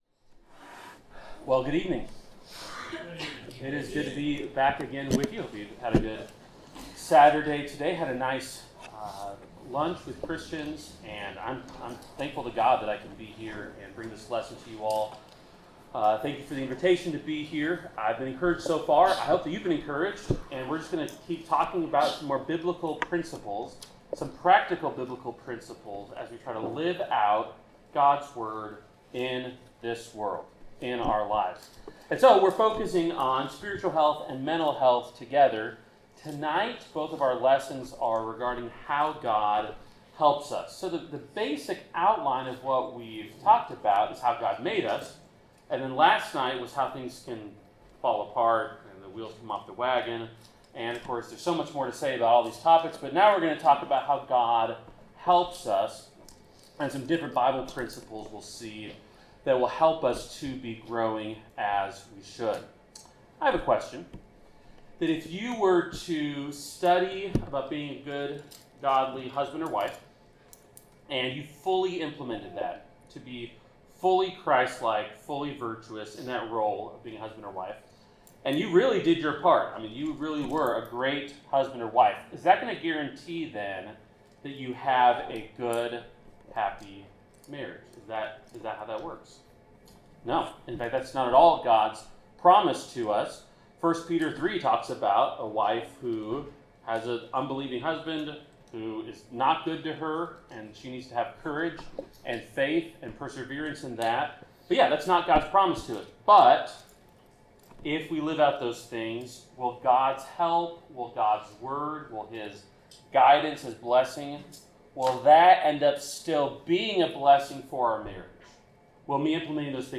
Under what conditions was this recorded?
Series: GROW Conference 2025 Service Type: Sermon